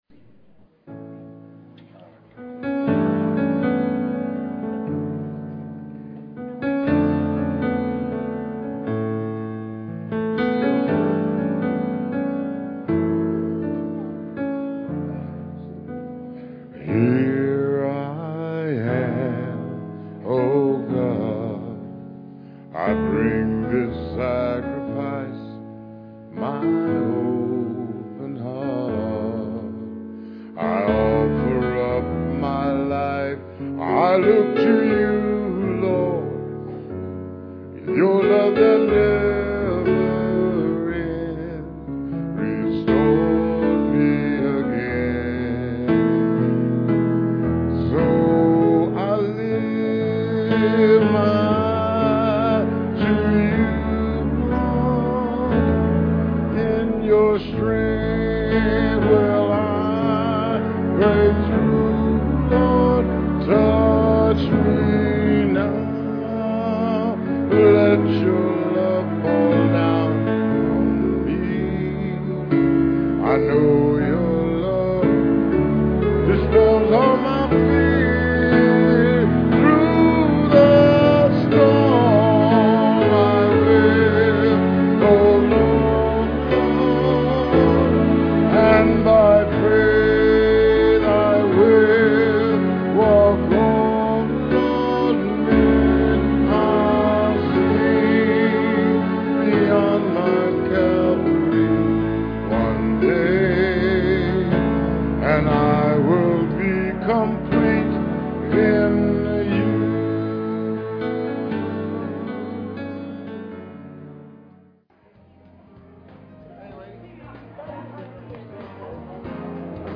Guitar and vocal solo